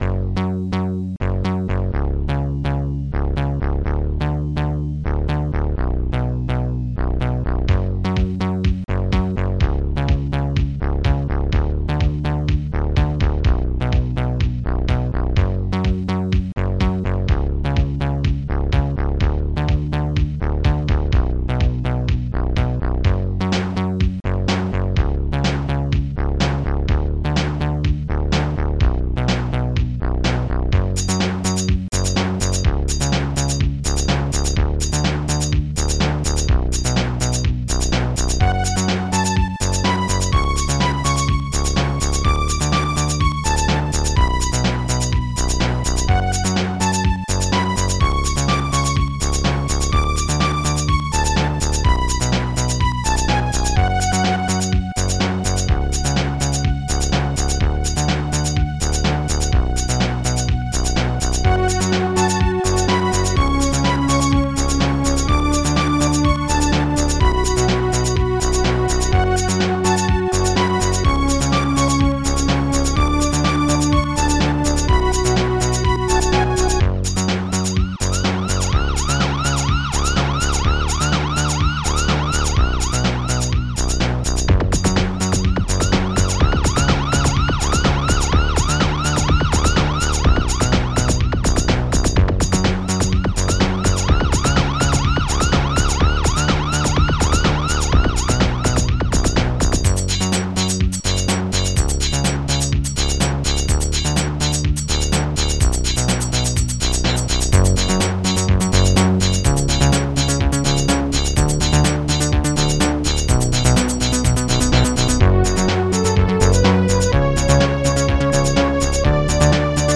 st-12:photon-bass
st-12:photon-snare2
st-12:photon-string
st-12:photon-laser
st-12:photon-tom